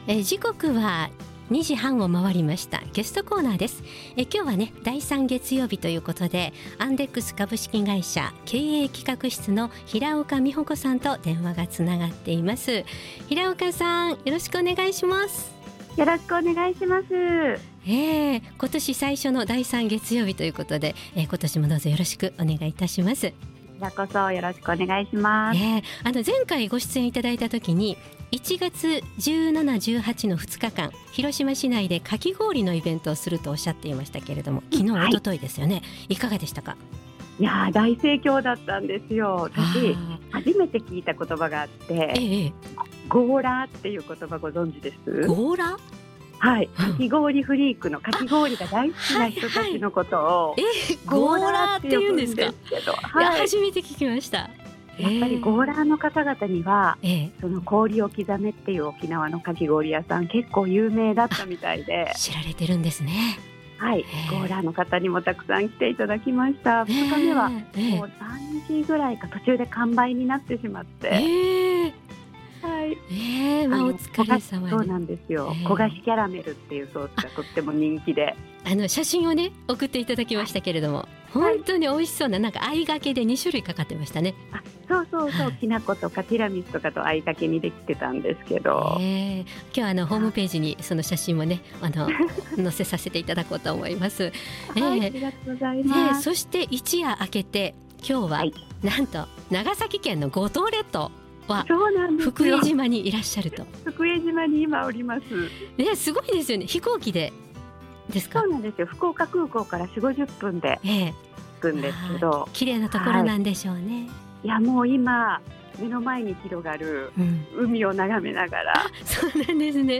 そのイベントの様子を電話でお聞きしています。